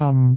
speech
cantonese
syllable
pronunciation